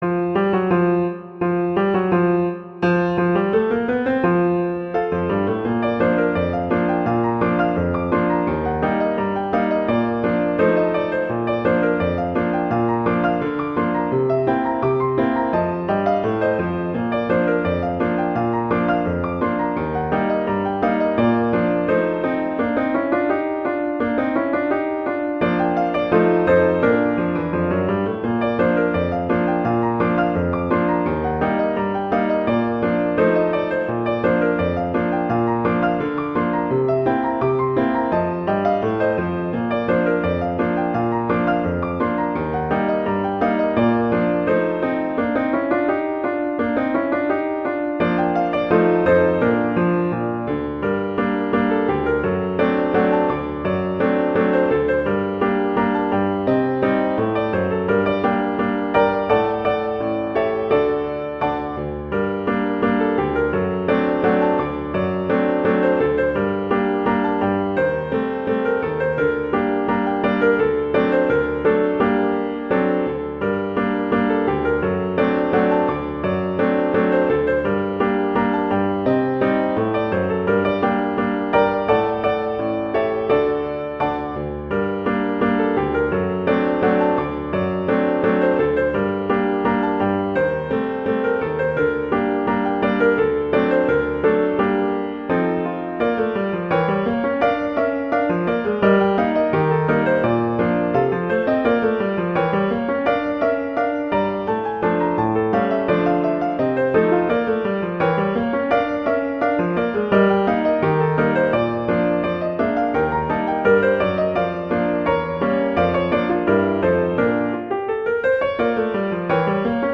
jazz, classical, multicultural